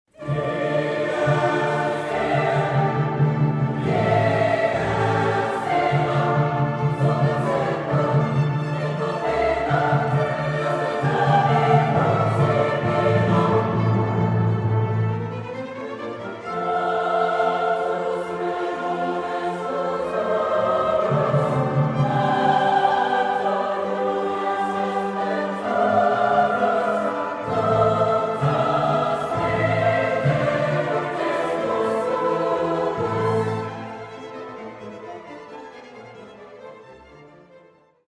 The Chamber Choir performing Mozart’s Requiem
WLU-Choir-Mozart-Requiem-MP3.mp3